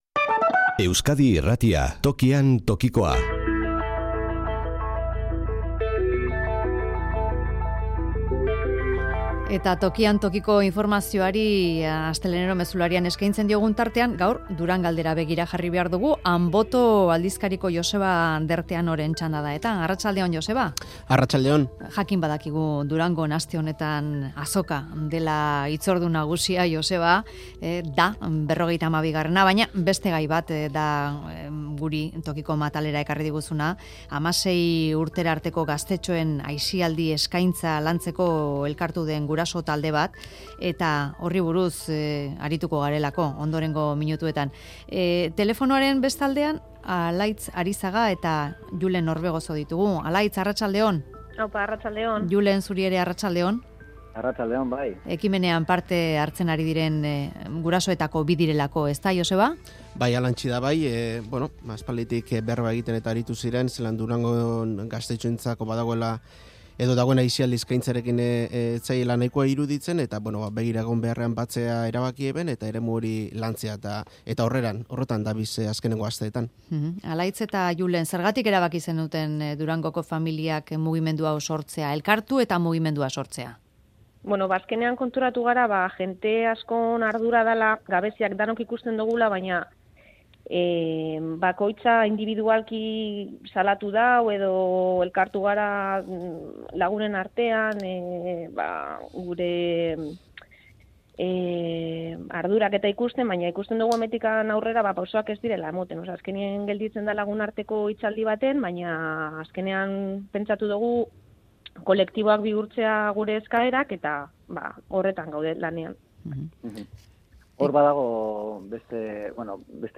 Gurasoetako bik eman dituzte azalpenak Mezularian.